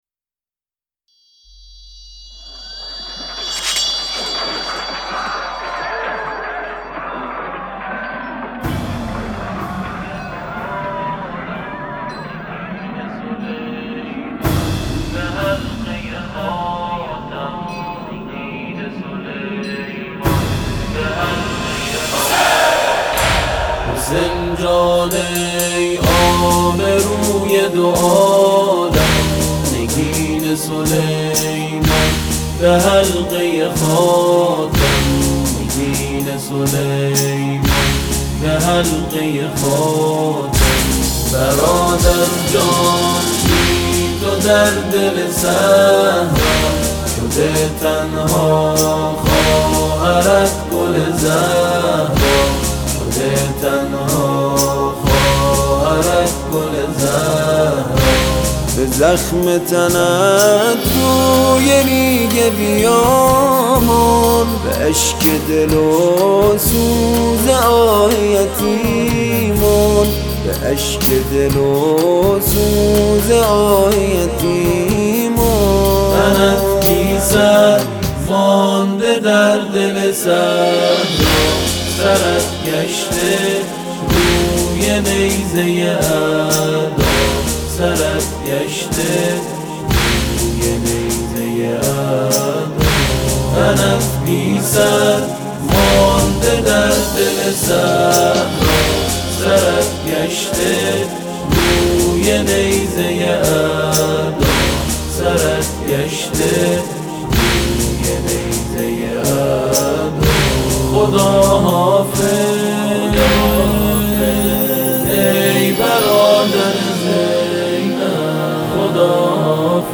تواشیح ، هم‌آوایی